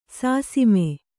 ♪ sāsime